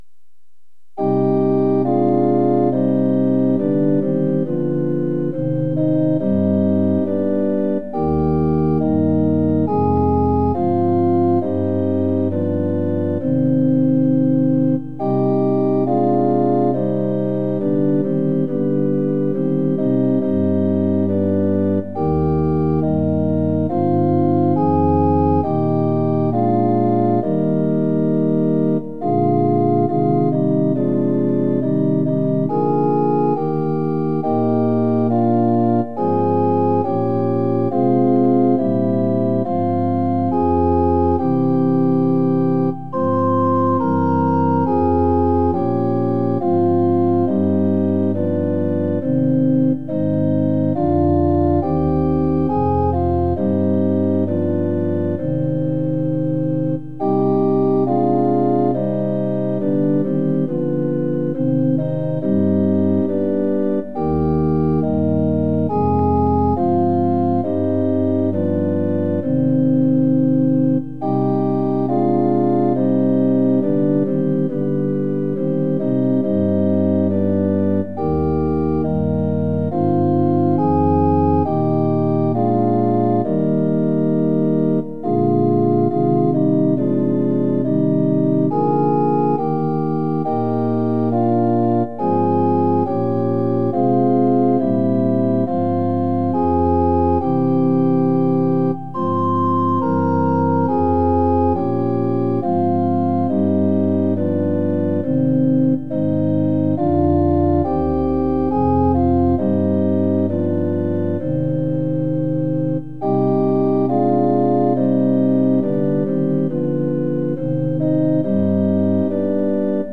◆　４分の４拍子：　一拍目から始まります。
●　「イエズス」は、「イエ・ズ・ス」の三音で発音します。